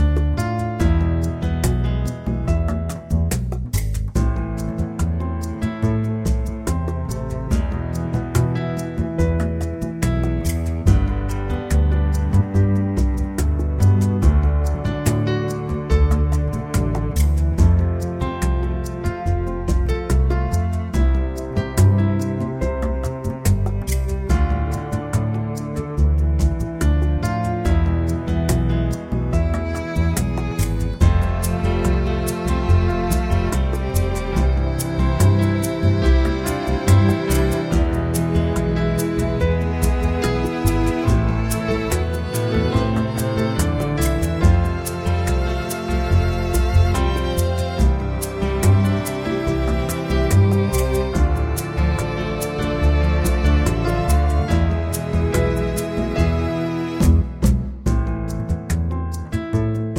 Up 4 Semitones For Female